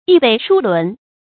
逸輩殊倫 注音： ㄧˋ ㄅㄟˋ ㄕㄨ ㄌㄨㄣˊ 讀音讀法： 意思解釋： 指超越同輩，無與比倫。